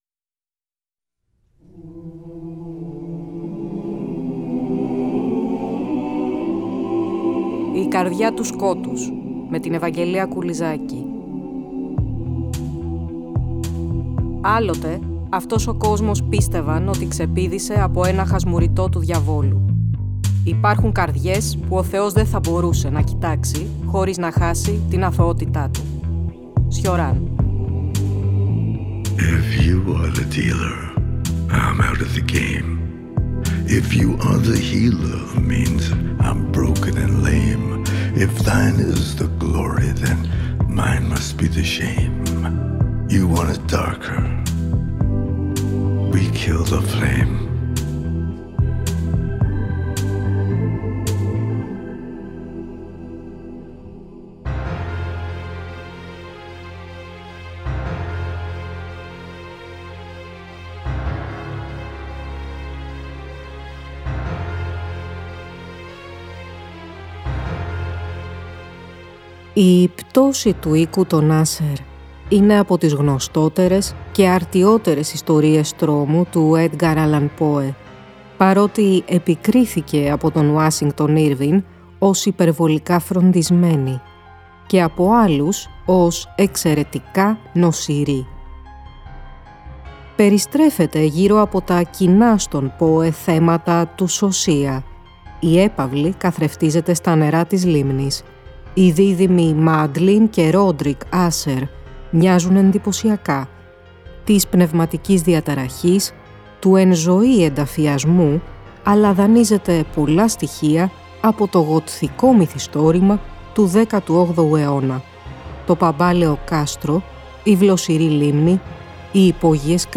Πλαισιώνουμε μουσικά με θραύσματα από τις ομώνυμες όπερες των Claude Debussy και Philip Glass.